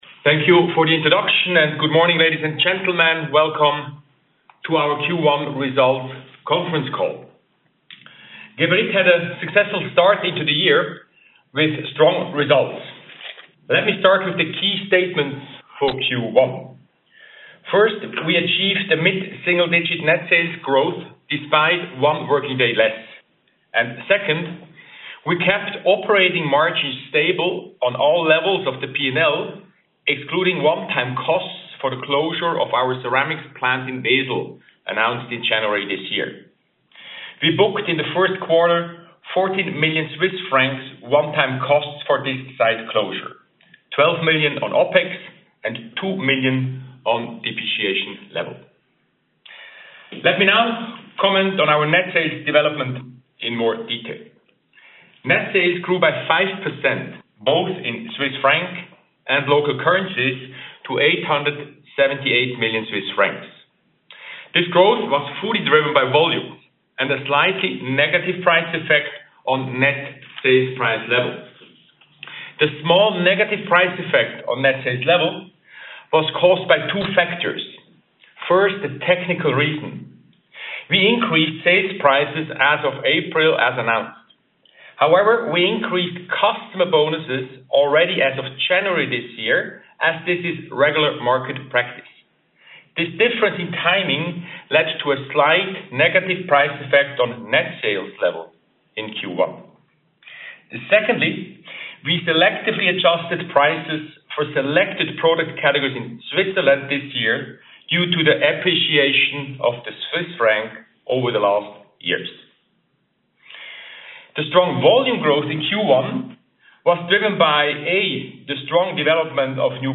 Die Aufzeichnung des Conference Call vom 15. Januar 2026 zur Veröffentlichung der ersten Informationen zum Geschäftsjahr 2025 kann hier (13 MB)abgehört werden.